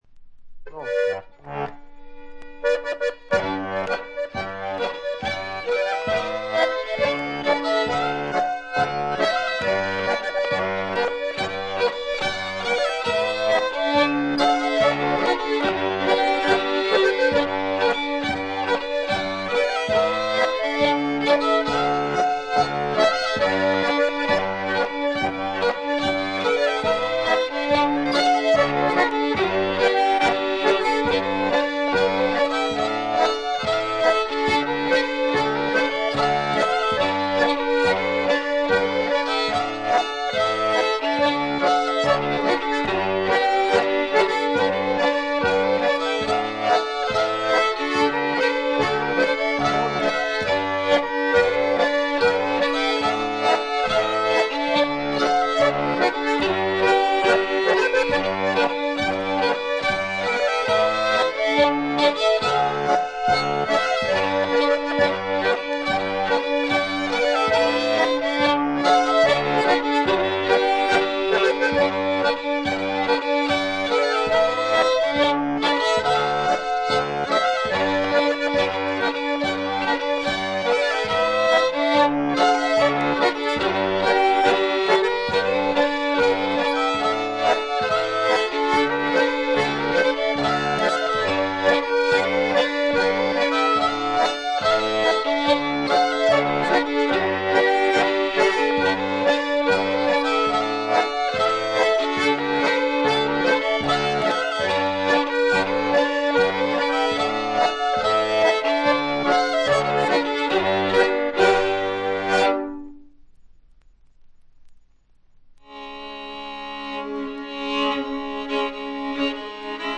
スウェーデンのトラッド・グループ
試聴曲は現品からの取り込み音源です。